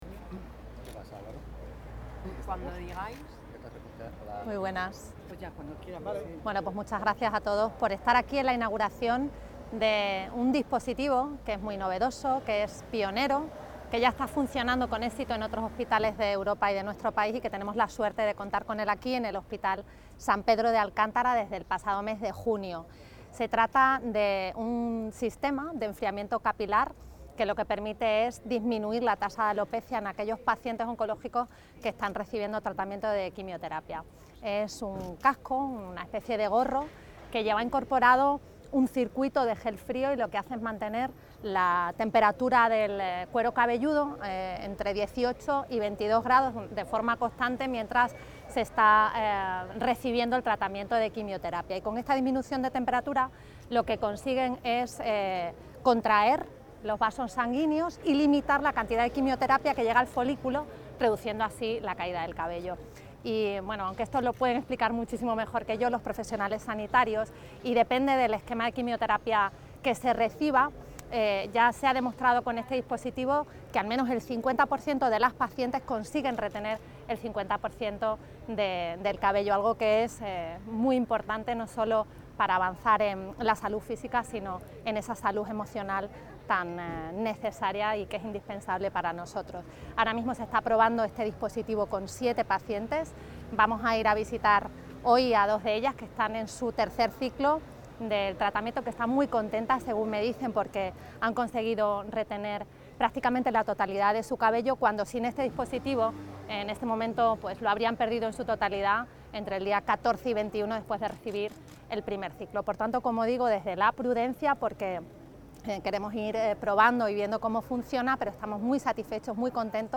Noticias Extremadura pone en marcha un dispositivo puntero para reducir la ca�da de cabello en pacientes oncol�gicos 23/08/2024 SALUD EXTREMADURA Documentos relacionados Declaraciones de la presidenta de la Junta, Mar�a Guardiola .